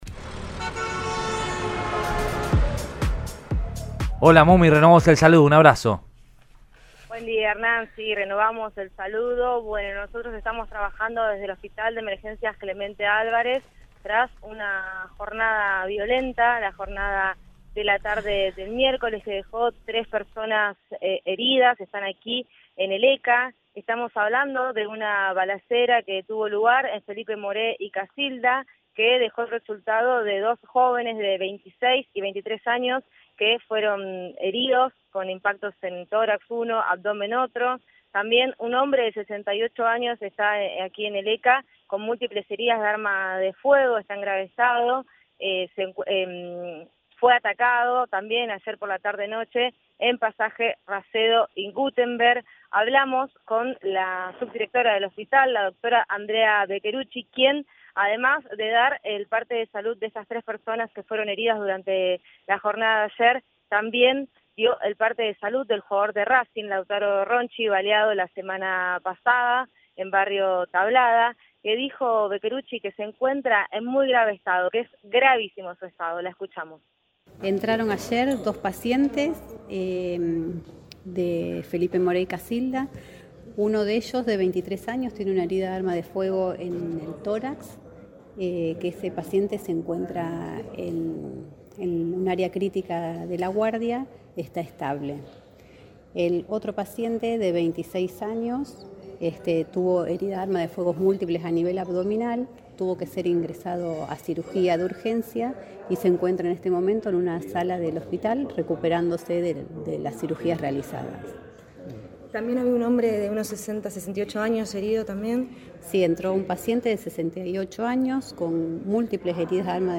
dialogó con el móvil de Cadena 3 Rosario, en Siempre Juntos